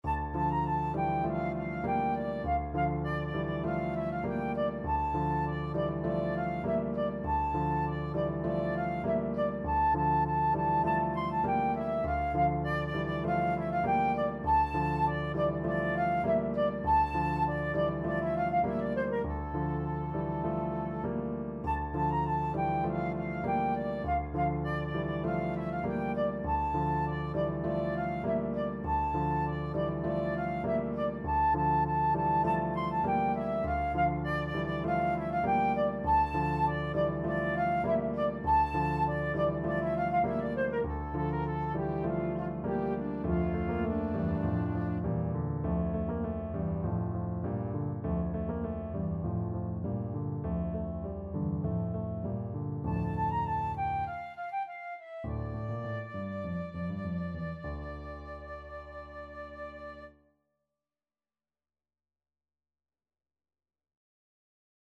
Classical Tchaikovsky, Pyotr Ilyich U Vorot (At the Gate) from 1812 Overture Flute version
Flute
Allegro (View more music marked Allegro)
D minor (Sounding Pitch) (View more D minor Music for Flute )
4/4 (View more 4/4 Music)
Classical (View more Classical Flute Music)